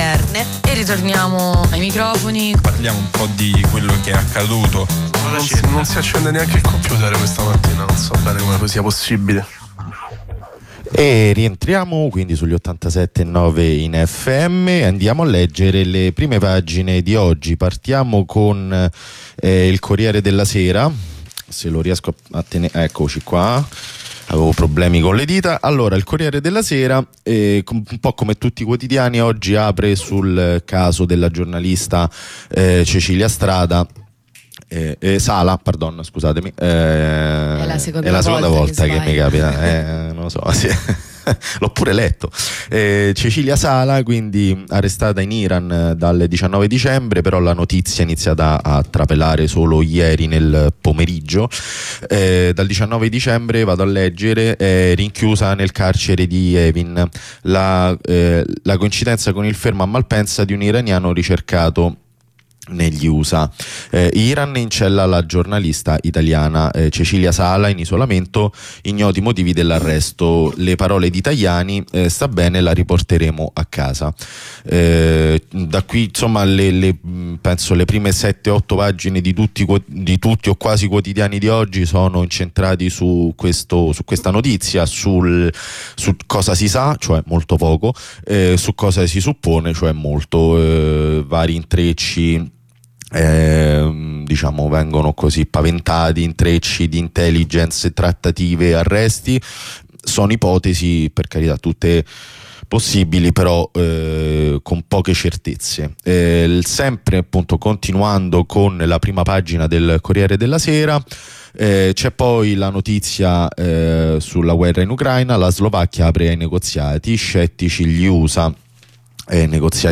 Lettura delle prime pagine e delle pagine interne dei quotidiani nazionali.